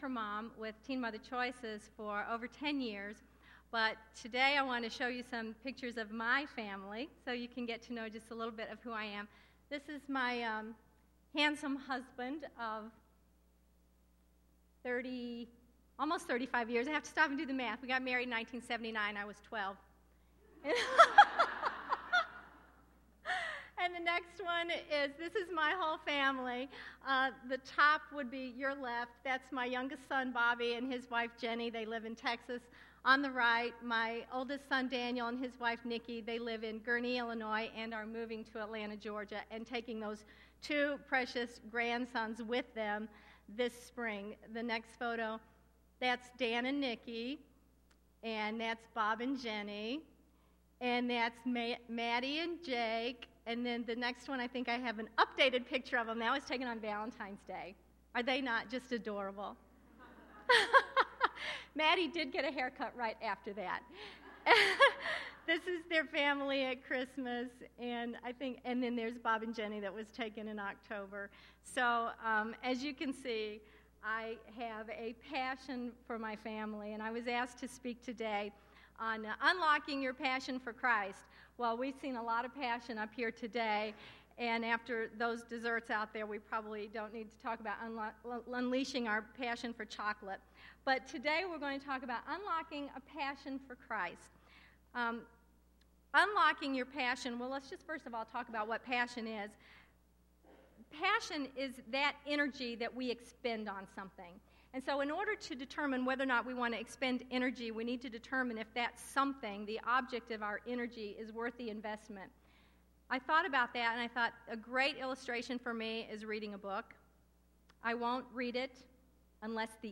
crown-point-womens-conference.mp3